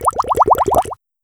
Bubbles